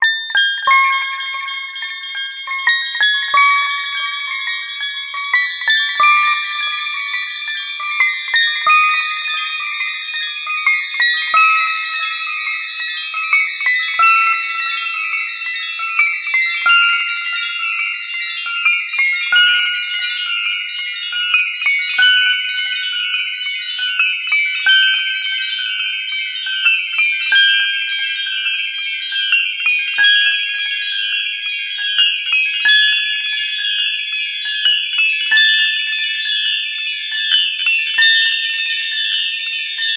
少しずつ高音になっていく可愛い音。